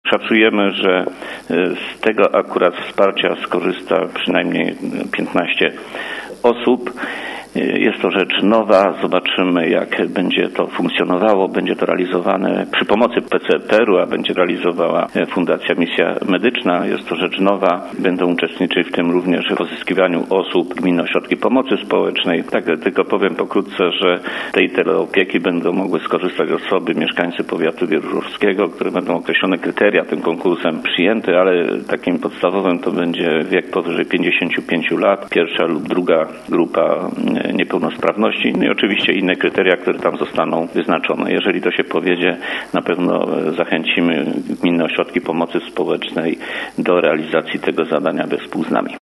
Mówi wicestarosta, Stefan Pietras: Szacujemy, że z tego wsparcia skorzysta przynajmniej 15 osób.